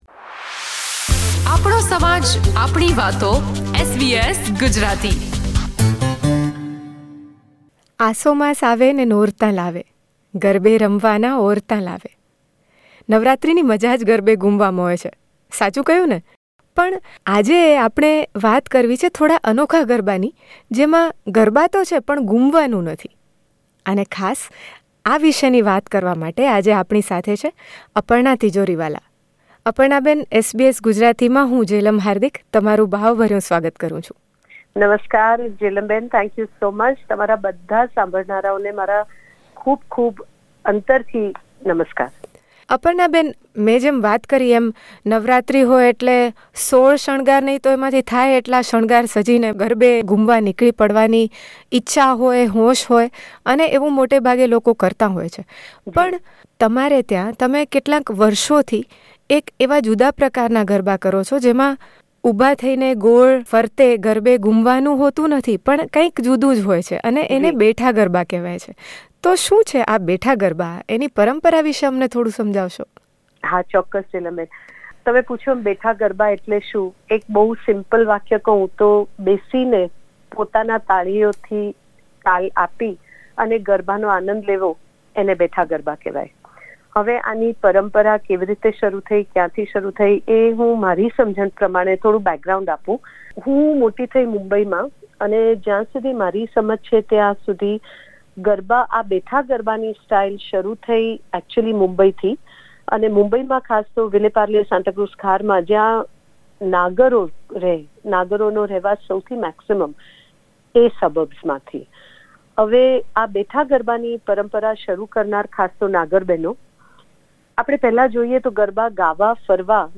ગરબાની કેટલીક મધુર કડીઓ ગાતાં- ગાતાં તેઓ યાદ કરે છે એમના જીવનના એક અણધાર્યા બનાવ વિષે જેણે એમને માતાજીને સ્મરવા માટે આ બેઠા ગરબા કરવાની પ્રેરણા આપી.